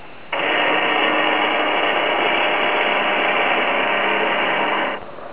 convert samples to 8Khz
flaps.wav